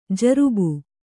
♪ jarubu